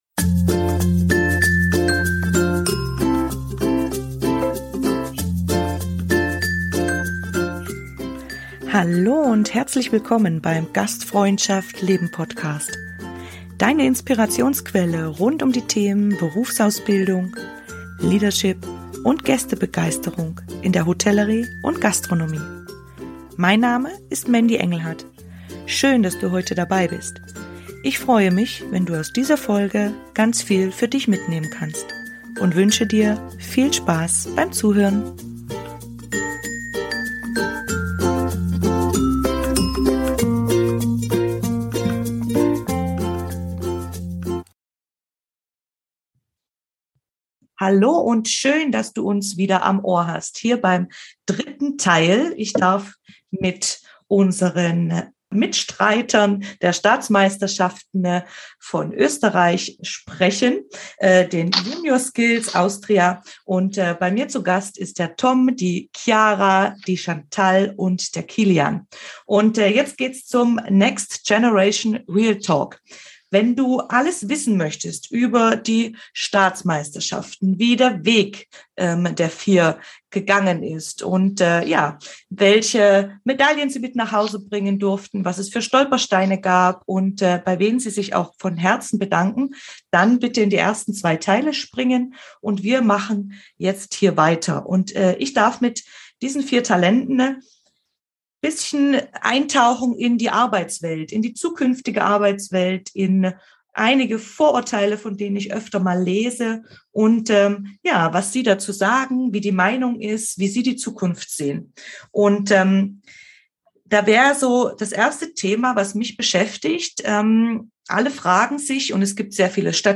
Ein authentisches, herzliches und ehrliches Gespräch erwartet dich.